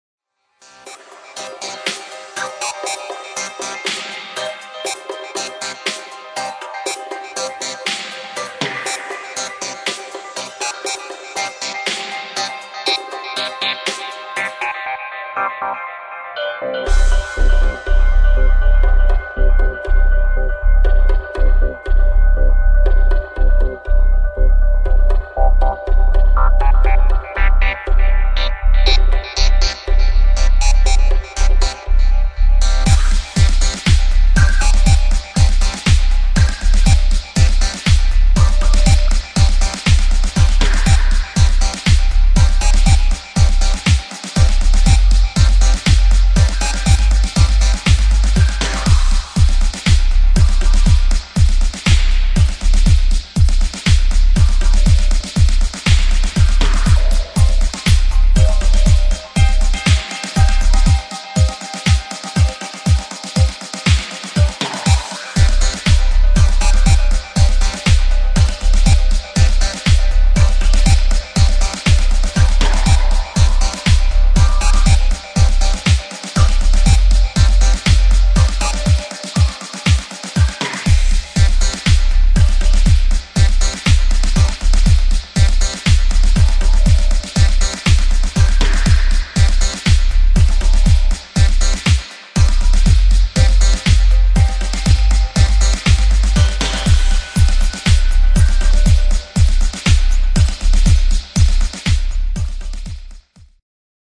[ DUB | REGGAE ]